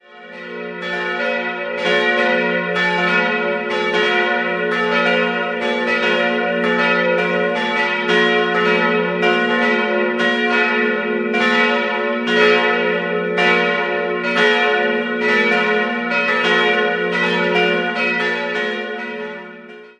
3-stimmiges TeDeum-Geläute: fis'-a'-h' Die Glocken wurden im Jahr 1979 von der Gießerei Bachert in Bad Friedrichshall gegossen.